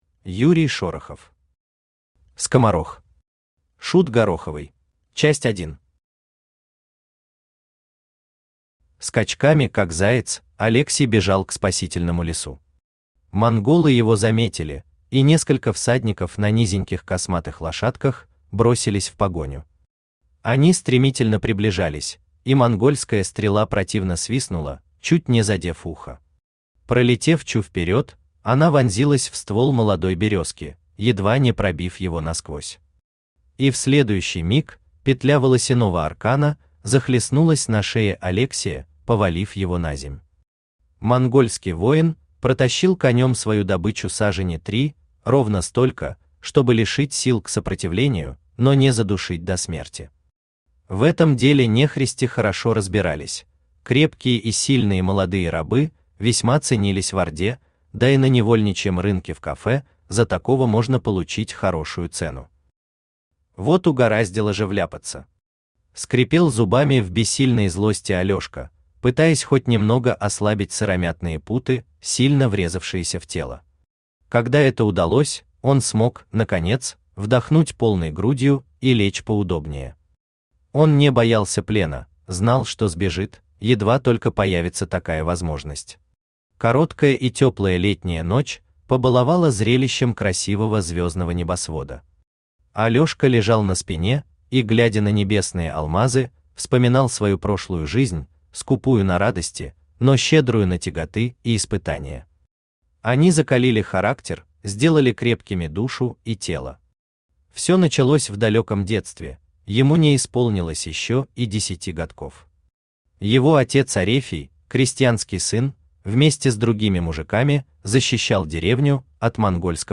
Аудиокнига Скоморох. Шут гороховый | Библиотека аудиокниг
Шут гороховый Автор Юрий Шорохов Читает аудиокнигу Авточтец ЛитРес.